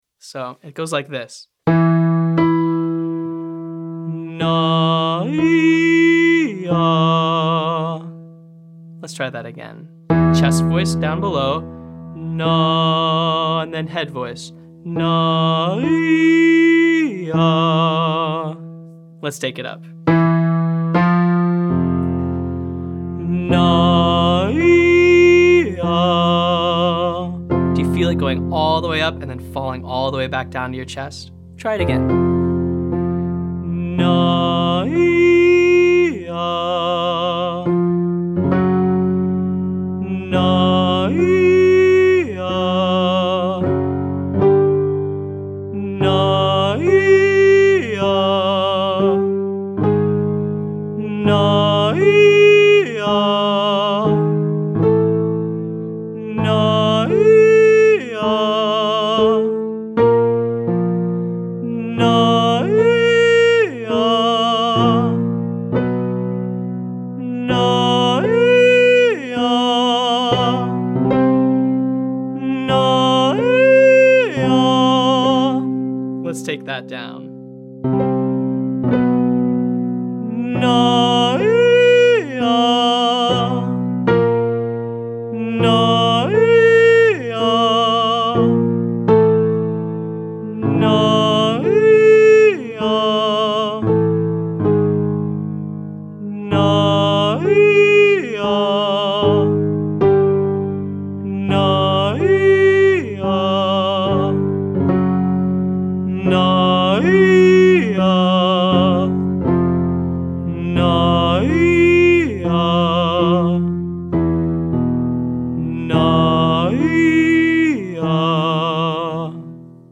• Exposed octaves between registers Nuh up to eee or ooo (1, 8, 1)